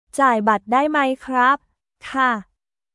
ジャーイ バット ダイ マイ クラップ/カー